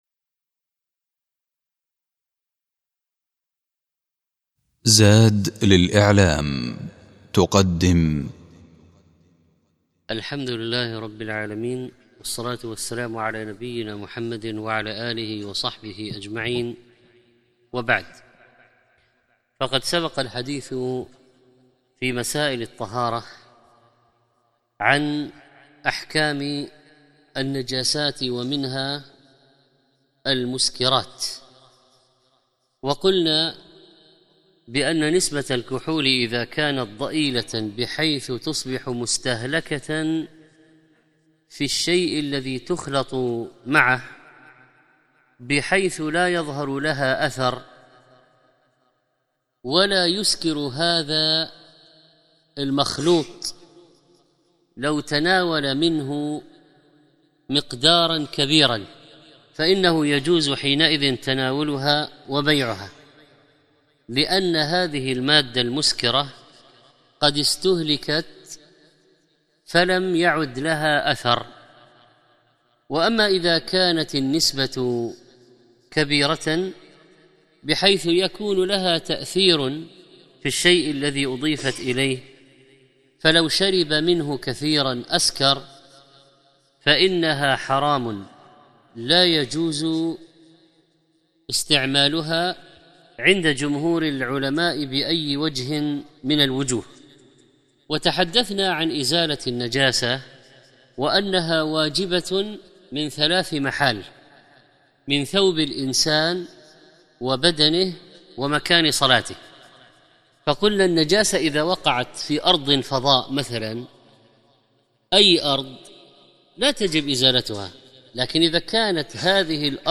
24 ذو القعدة 1432 الزيارات: 5510 تحميل تحميل ملف فيديو تحميل ملف صوتي أحكام النجاسة - الدرس الحادي عشر تحدث الشيخ في هذا الدرس عن أحكام النجاسة الجامدة، وعن كيفية تطهير النجاسة المائعة إذا أصابت الأرض، أو شيئا لا يتشرب النجاسة، وبين الحكم إذا تعسر إزالة لون النجاسة أو رائحتها، وذكر أنه لا يشترط لغسل النجاسة عدد معين، وبين حكم قياس نجاسة الخنزير على الكلب، ووضح الأحكام المتعلقة بنجاسة الغلام الرضيع والجارية، وبين كيفية تطهير البئر إذا وقعت فيها نجاسة، وذكر حكم إزالة النجاسة بغير الماء من المائعات، وبين كيفية تطهير الأسطح الصقيلة، وذكر أحكام الطهير بالجفاف والدلك وتكرار المشي في الثوب الطويل، وختم بذكر حكم التطهير بالبخار.